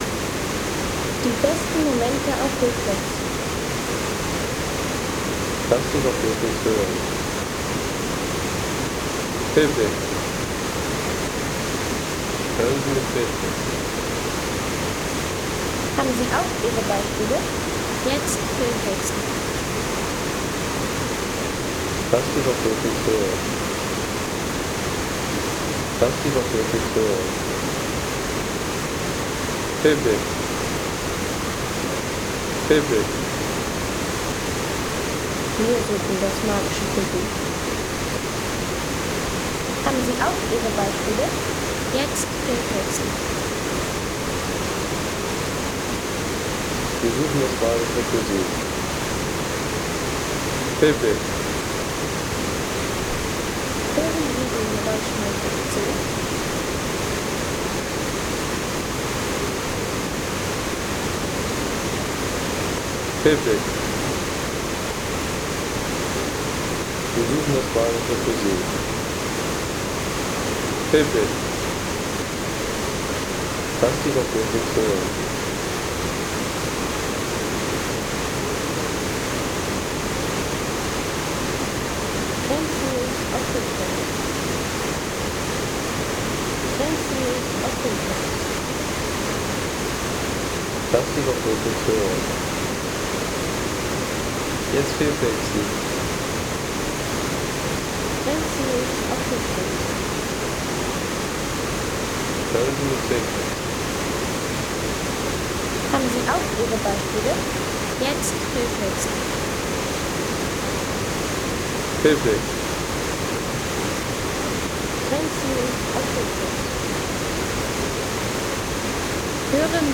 Ein Wasserfall-Sound mit alpiner Wucht
Kraftvoller Wasserfall-Sound vom Lechfall in Füssen.
Der Lechfall in Füssen als lebendige Naturkulisse für Filme, Postkarten, Reiseinhalte und atmosphärische Hintergrundszenen.